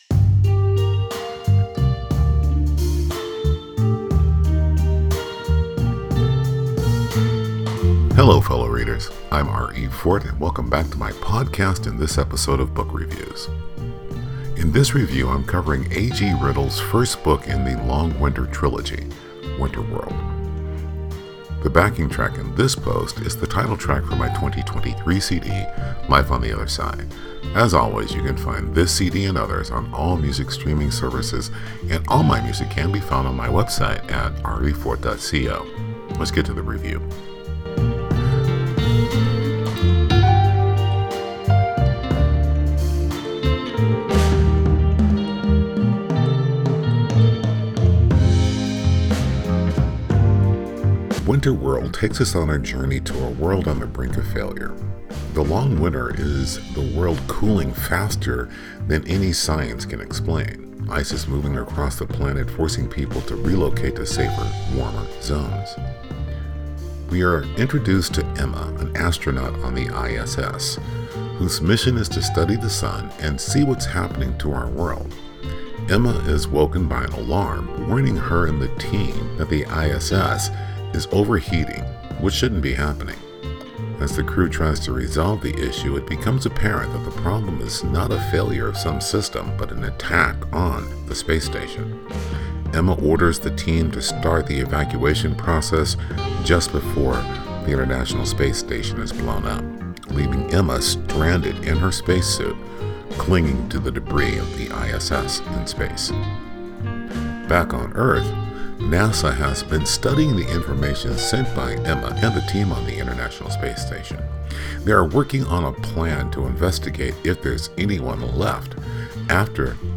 Book-Review-Winter-World.mp3